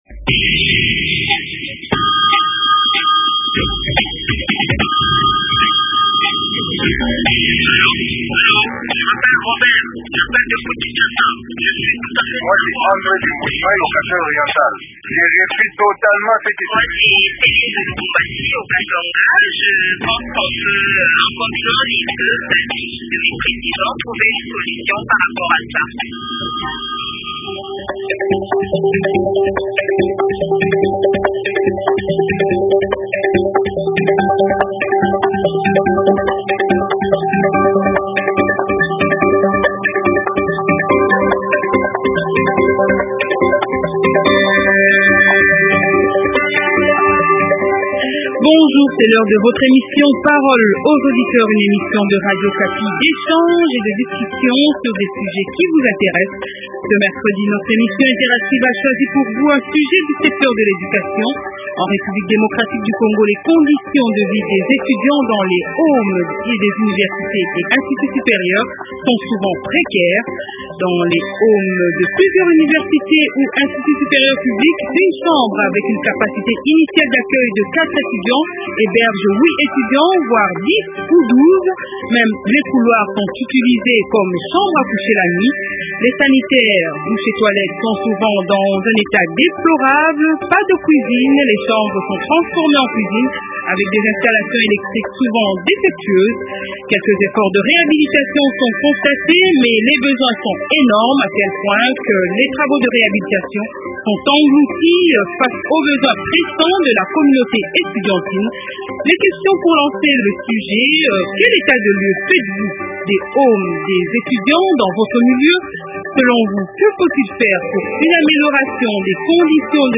Invités : Professeur Mashako Mamba,Ministre national de l’enseignement supérieur et universitaire.